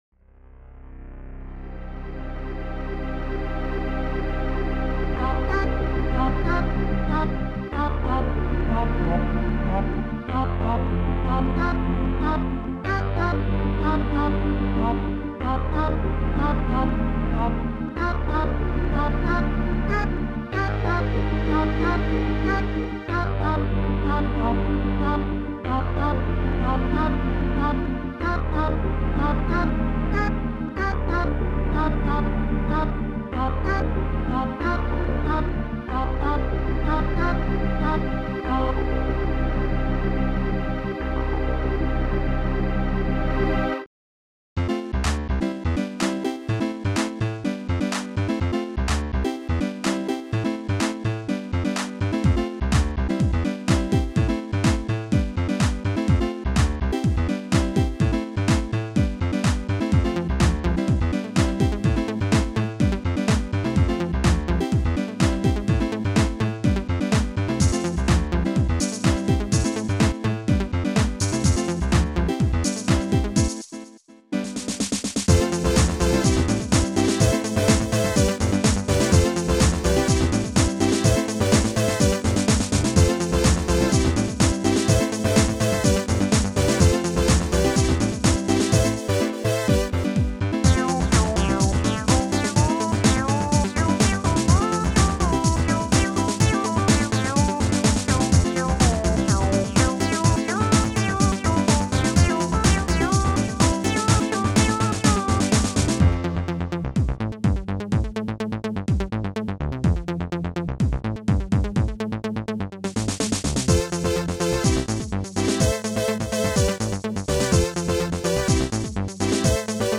Protracker M.K.
from s5552-soundcard.